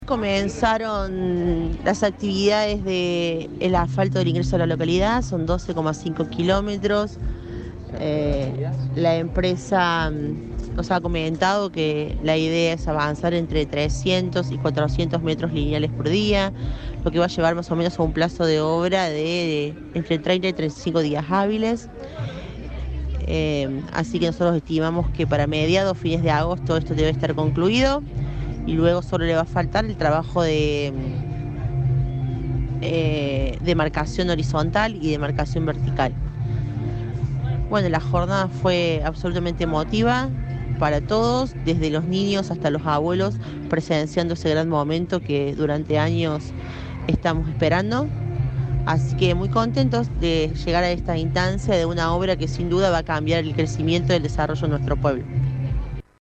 Leticia Allocco, intendente de Silvio Pellico, habló con nuestro medio y brindó más detalles.